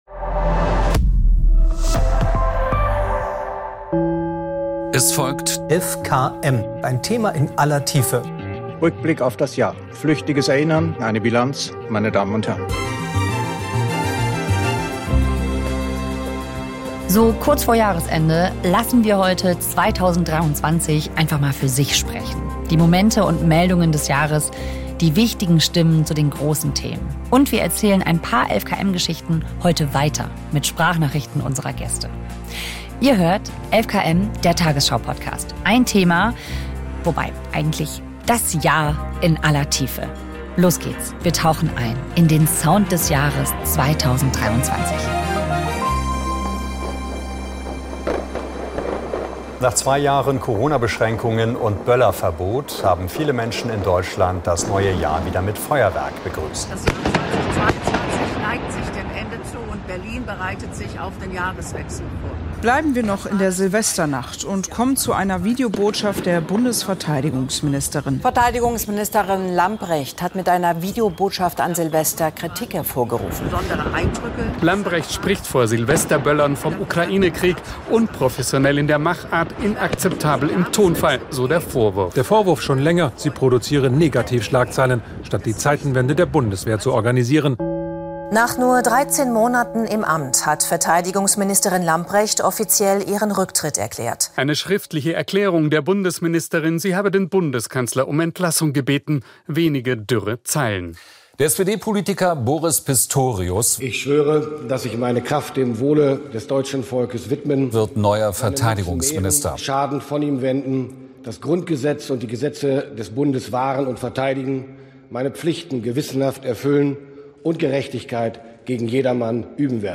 So kurz vor Jahresende lassen wir 2023 für sich sprechen: Die Momente und Meldungen des Jahres, die wichtigen Stimmen zu den großen Themen in einer ganz besonderen 11KM-Folge. Und wir erzählen ein paar 11KM-Geschichten heute weiter, mit Sprachnachrichten unserer Gäste.